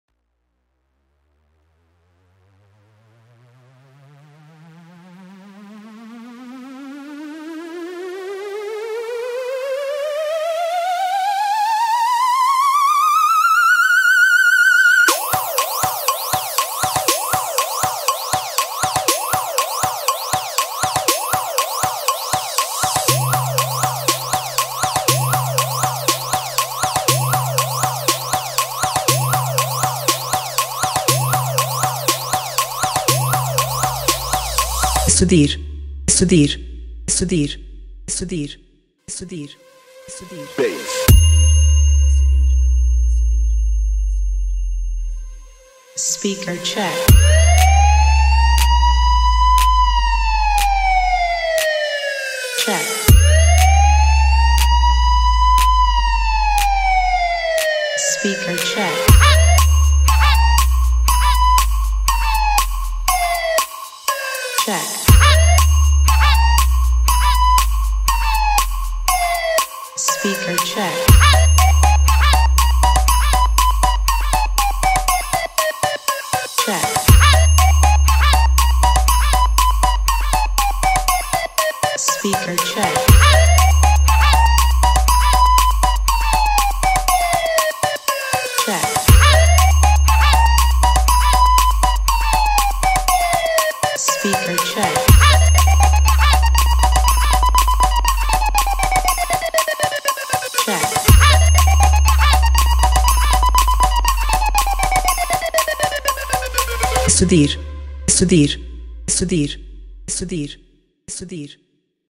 اهنگ تکنو پلیسی شوتی مخصوص ماشین شوتی